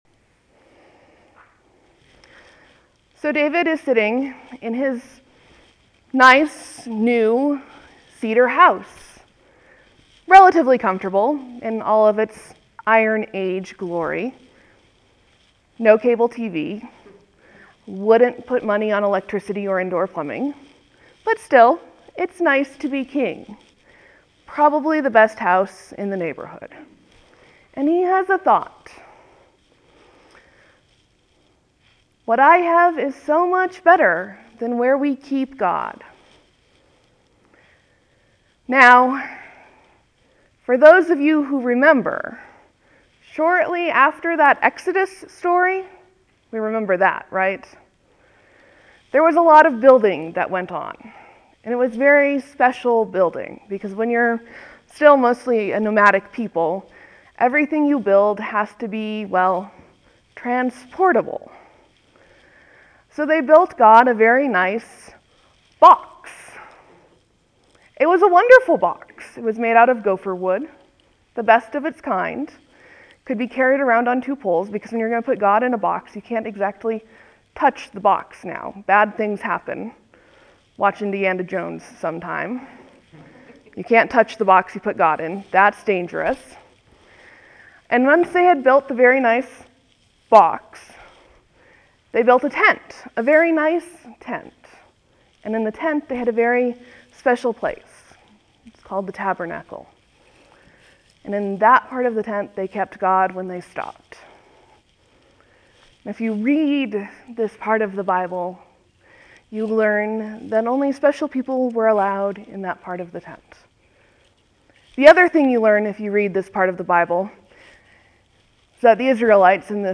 (There will be a few moments of silence before the sermon begins. Thank you for your patience.)